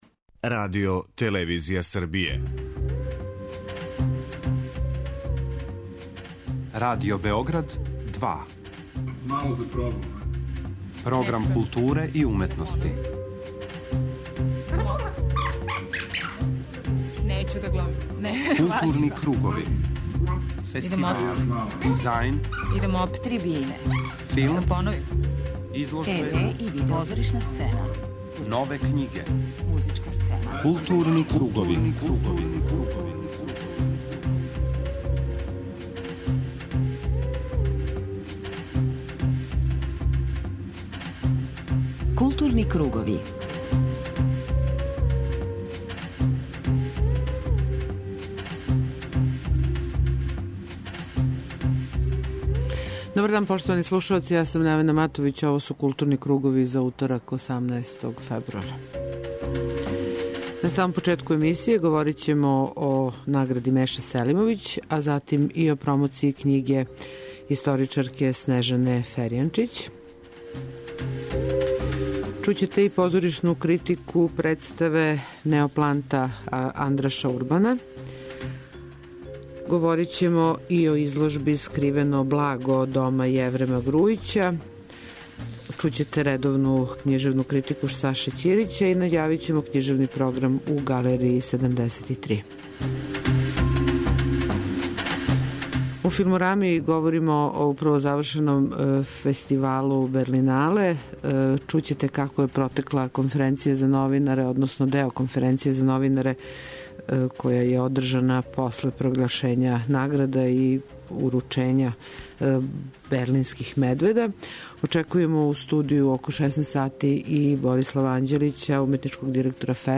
У првом делу емисије бележимо назначајније догађаје из културе, а у 'Филморами' говоримо о 10. 'Нордијској панорами' и 42. ФЕСТ-у, а чућете и прве реакције награђених на управо завршеном 'Берлиналу'.
Захваљујући љубазности прес службе фестивала, чућете како је протекла завршна конференција за новинаре, на којој су се новинарима представили аутори награђених филмова.